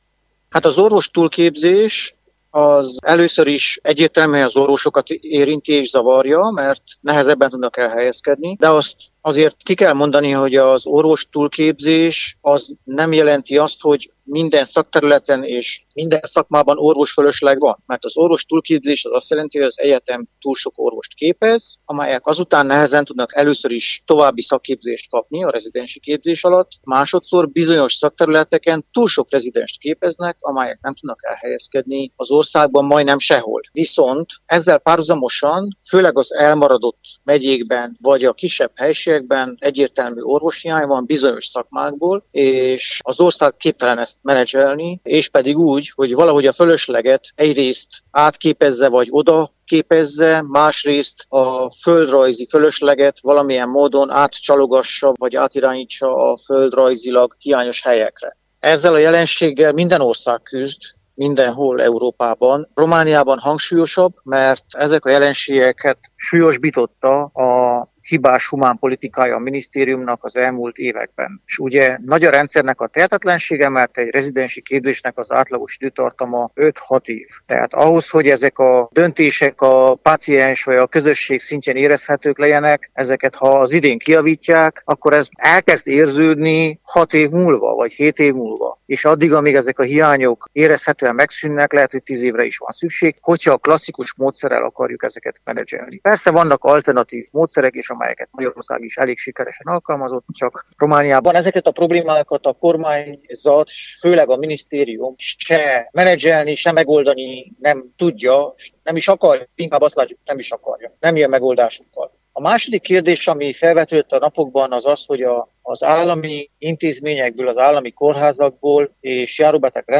orvos-közgazdászt kérdezte